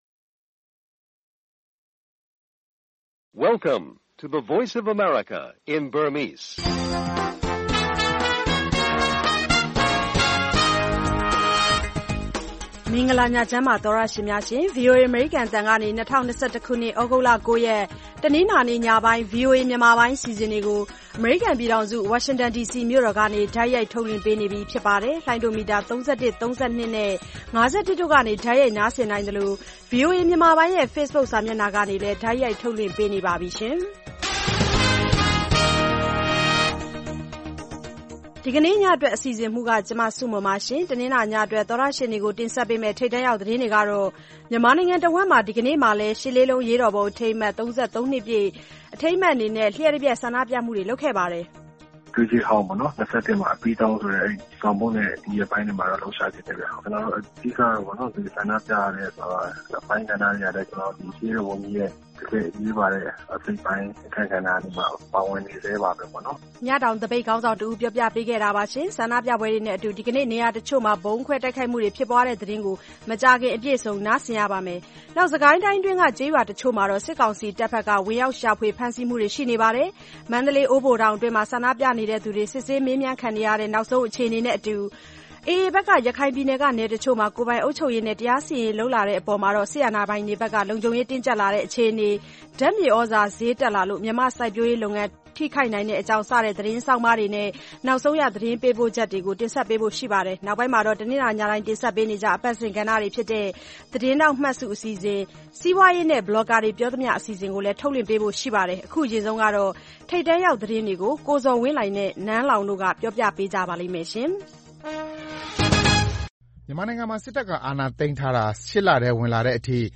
VOA ရေဒီယိုညပိုင်း ၉း၀၀-၁၀း၀၀ တိုက်ရိုက်ထုတ်လွှင့်မှု(သြဂုတ်၉၊၂၀၂၁)